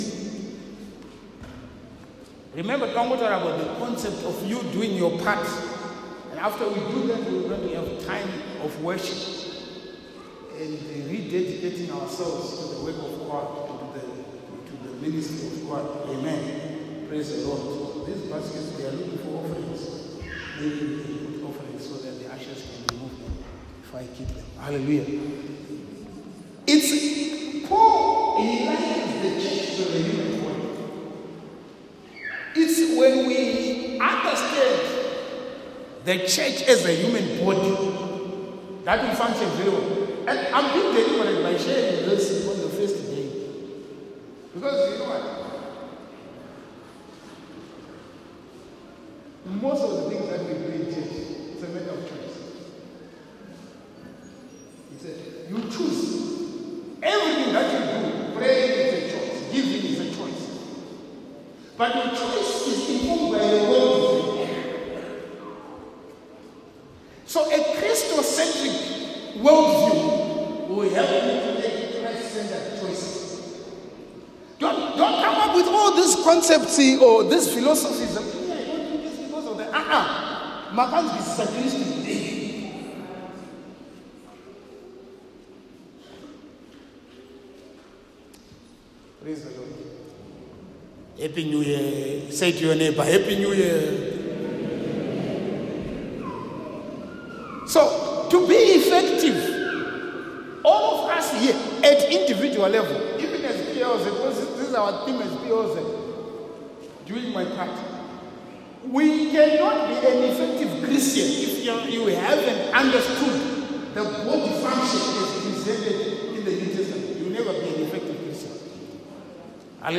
Sermons | Barnabas Leadership Outreach Center